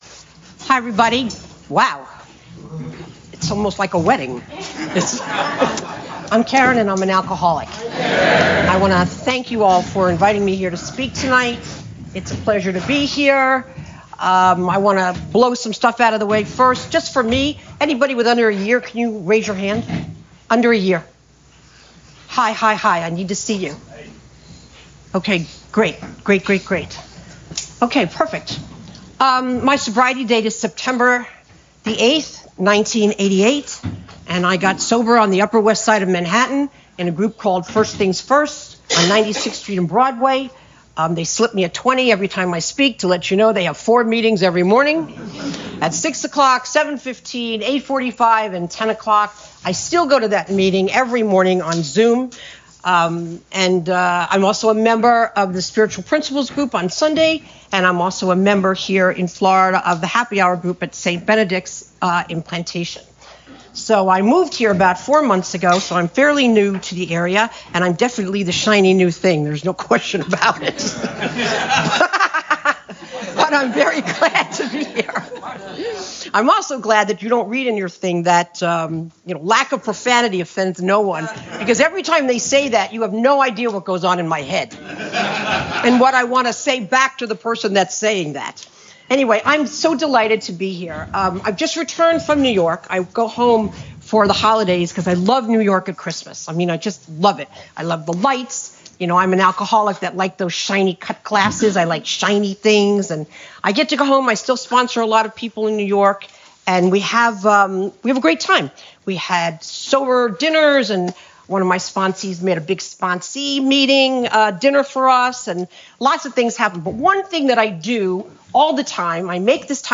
East Naples Men’s Group 37th Anniversary Dinner 2025
AA Speaker Recordings AA Step Series Recordings Book Study